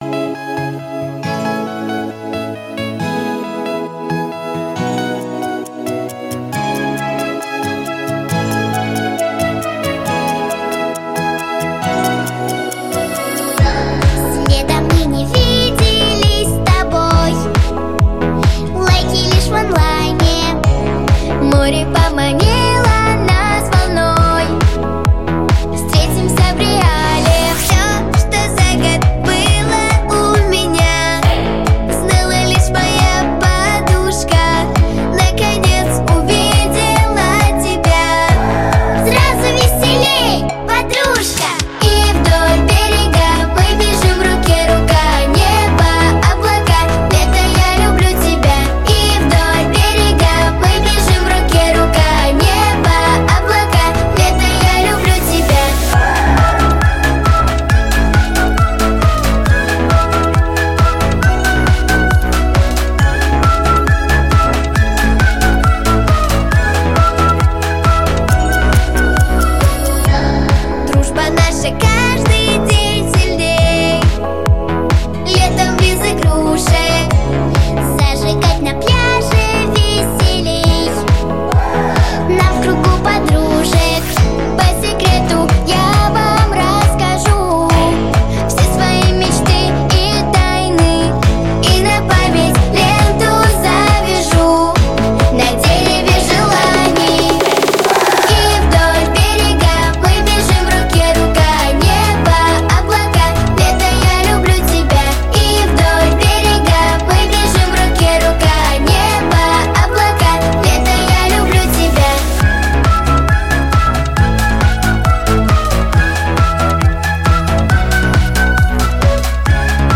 🎶 Детские песни / Времена года / Песни про Лето 🌻